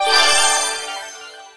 get_pickup_04.wav